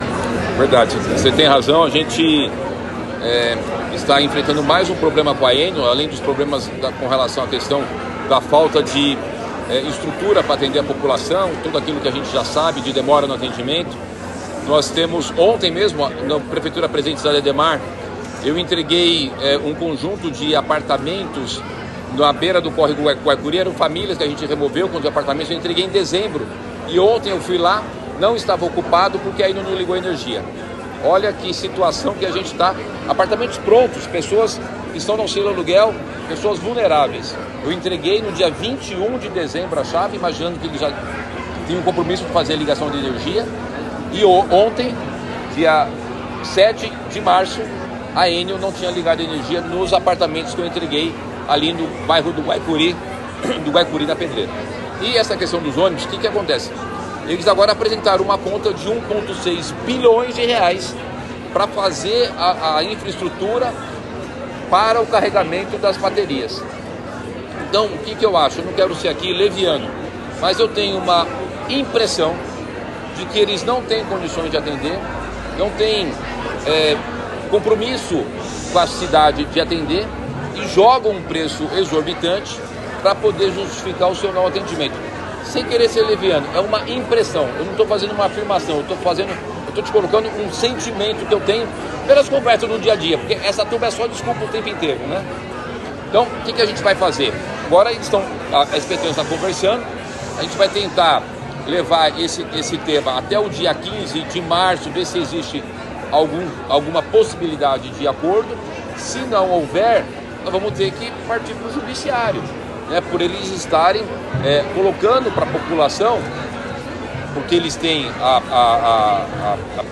Na manhã desta sexta-feira, 8 de março de 2024, o prefeito de São Paulo, Ricardo Nunes, voltou a falar sobre o tema quando questionado em entrevista coletiva.